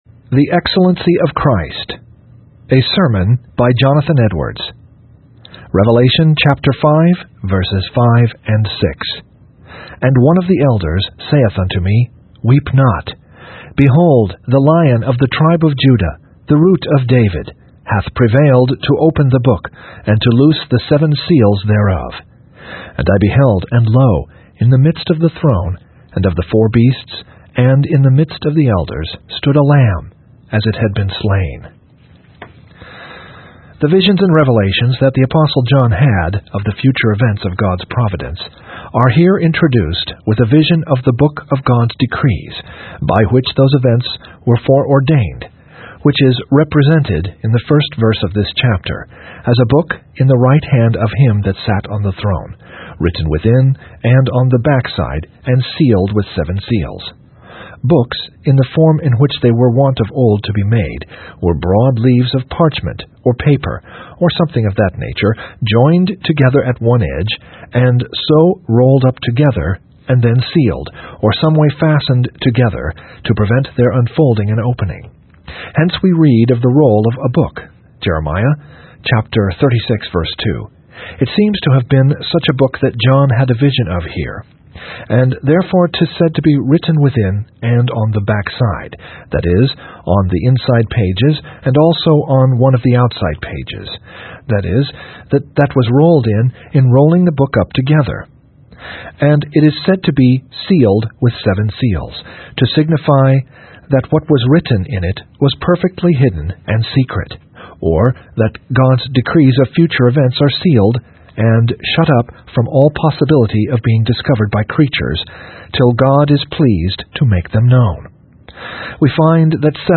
In this sermon by Jonathan Edwards, he preaches on the excellency of Christ, focusing on Revelation 5:5-6. Edwards emphasizes that it is unnecessary for people to labor and toil for things that cannot satisfy their souls.